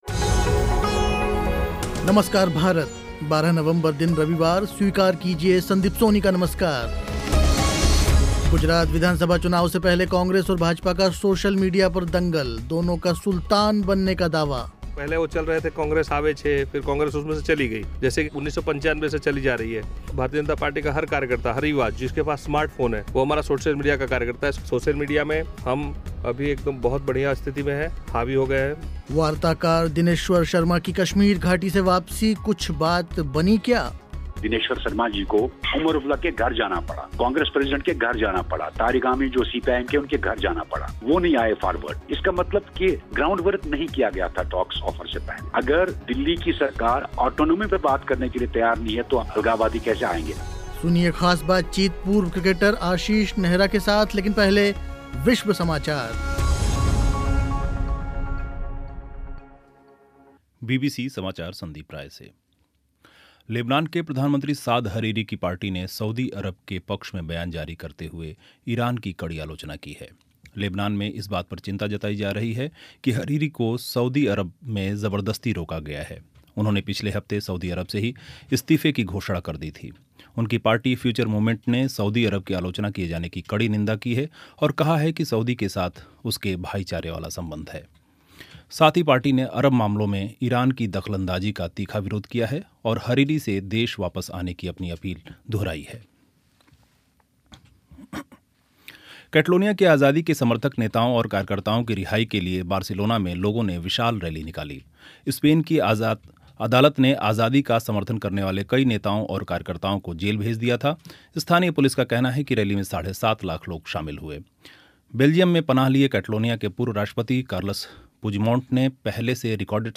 गुजरात विधानसभा चुनाव से पहले कांग्रेस और भाजपा का सोशल मीडिया पर दंगल. वार्ताकार दिनेश्वर शर्मा की कश्मीर घाटी से वापसी, कुछ बात बनी क्या? सुनिए ख़ास बातचीत पूर्व क्रिकेटर आशीष नेहरा के साथ.